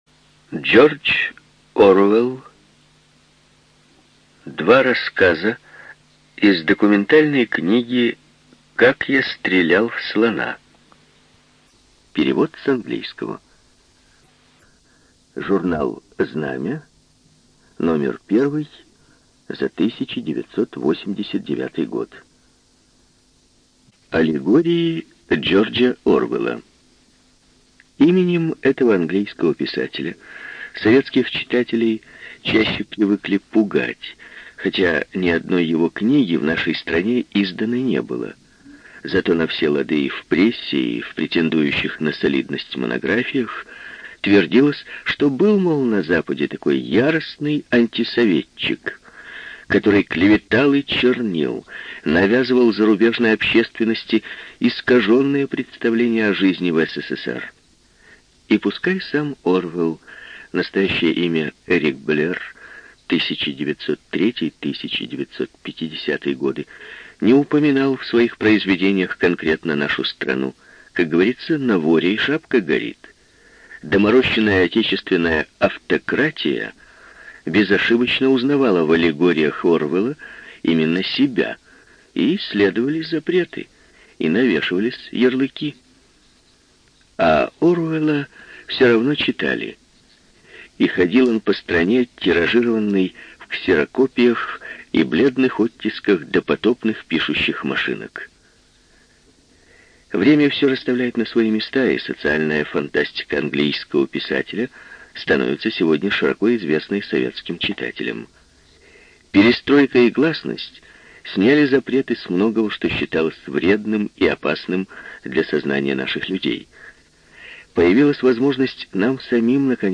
ЖанрКлассическая проза
Студия звукозаписиРеспубликанский дом звукозаписи и печати УТОС